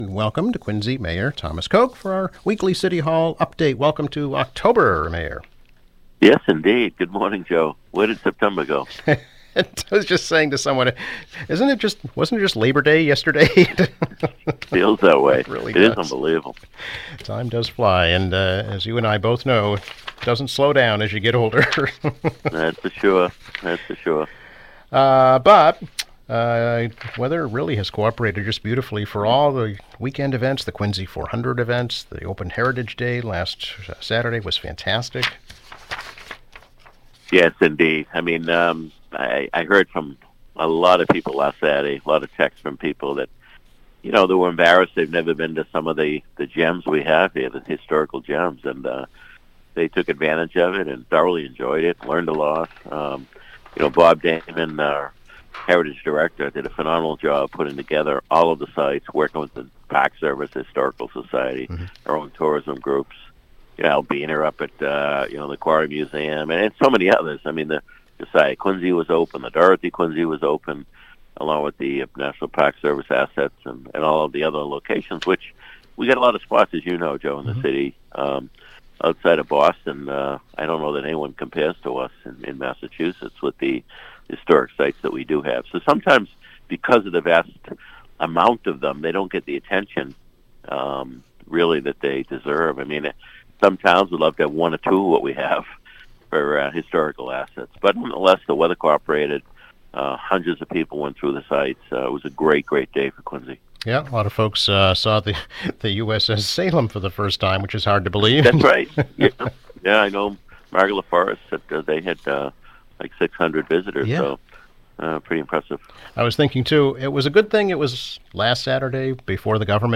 Mayor Thomas Koch comments on the federal government shutdown, the MBTA Water Transportation Study, the annual Food Truck and Music Festival, and the 250th anniversary of the U.S. Navy.